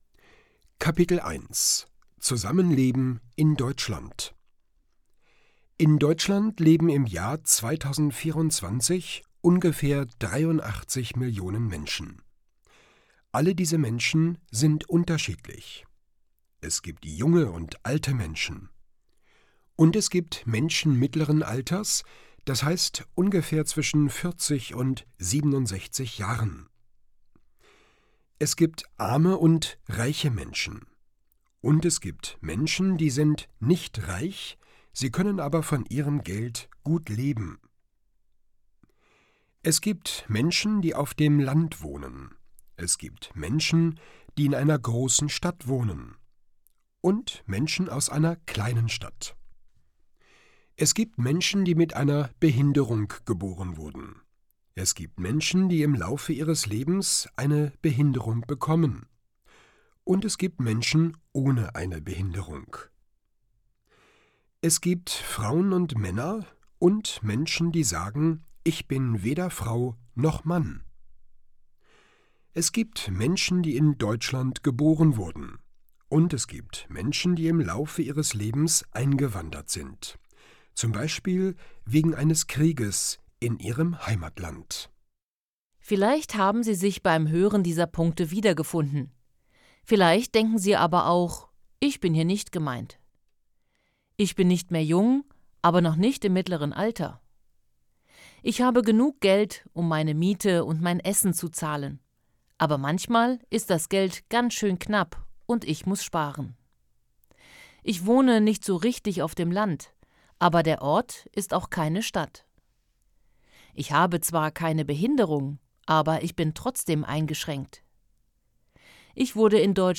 Kapitel 1: Zusammenleben in Deutschland Hörbuch: „einfach POLITIK: Zusammenleben und Diskriminierung“
• Produktion: Studio Hannover